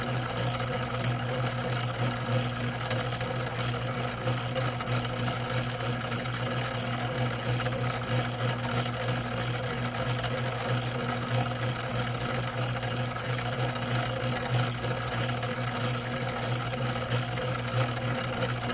金属加工厂" 机床 金属切割器 磨刀机 滚筒 关闭3
Tag: 切割机 关闭 机器 金属 粉碎机